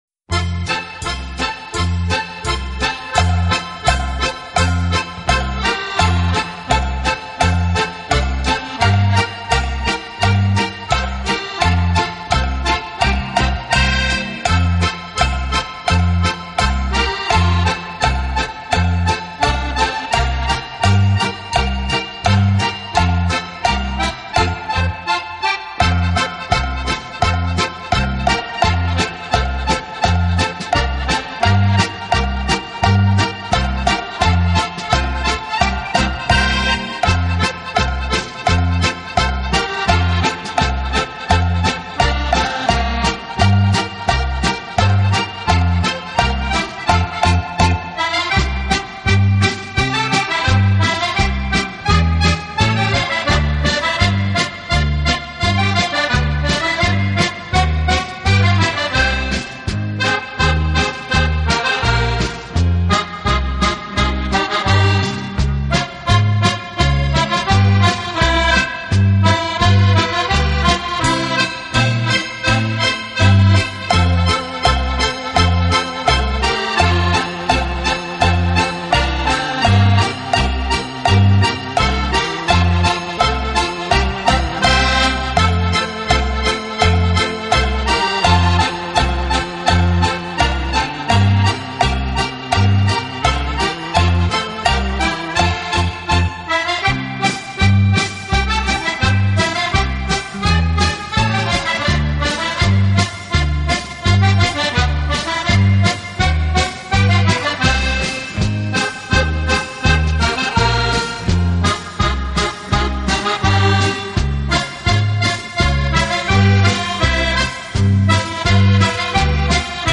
【手风琴】